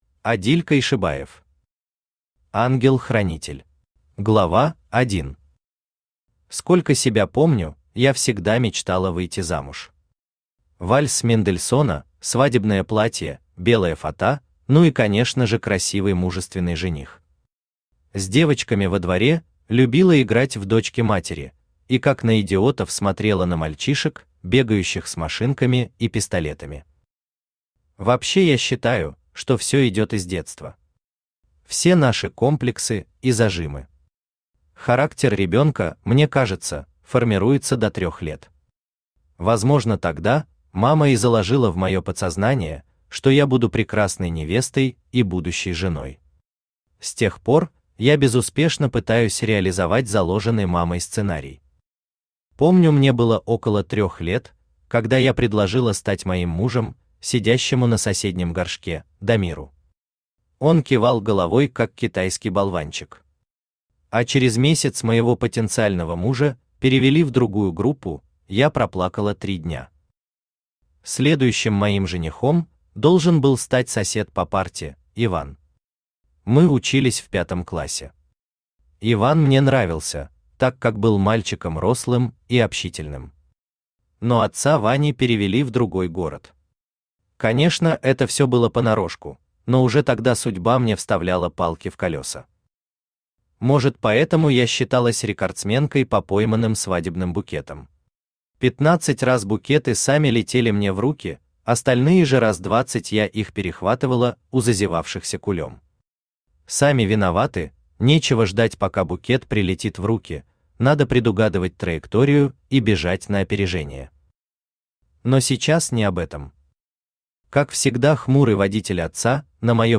Аудиокнига Ангел-хранитель | Библиотека аудиокниг
Aудиокнига Ангел-хранитель Автор Адиль Койшибаев Читает аудиокнигу Авточтец ЛитРес.